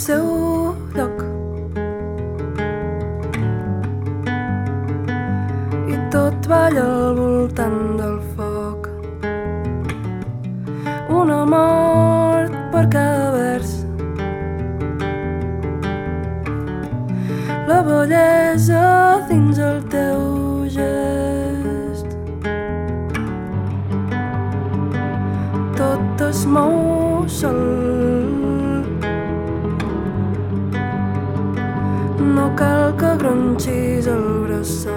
Singer Songwriter